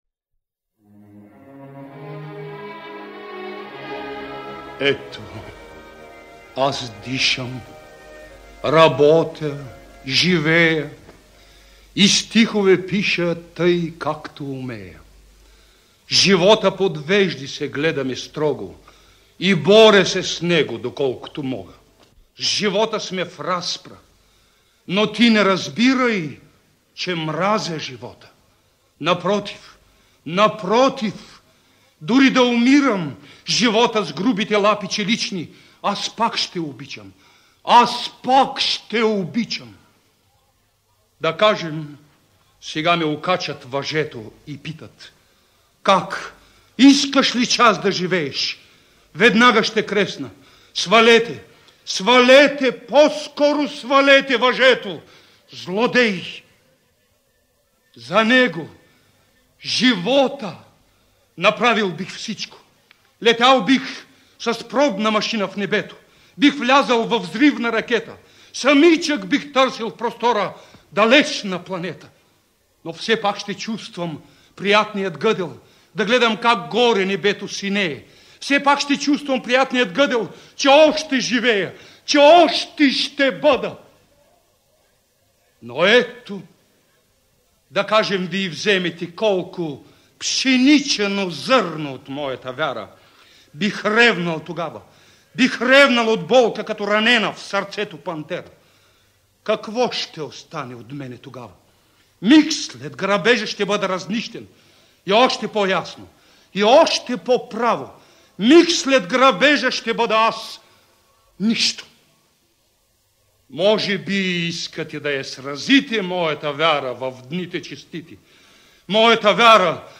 Този негов стремеж към идеала за по-добър живот поетът е изразил в „стихове както умея“( „Вяра“ в изпълнение на Иван Димов):